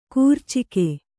♪ kūrcike